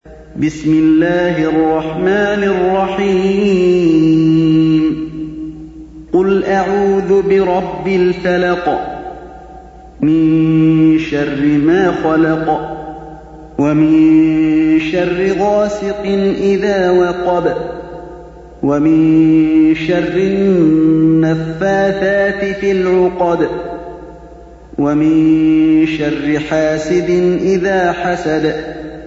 récitation mp3 (lente et claire pour apprentissage) - Hafidh Alî al-Hudayfi (qdlfm) - 114 ko ;
113-Surat_Al_Falaq_(L_aube_naissante)_Cheikh_Ali_Al_Hudayfi.mp3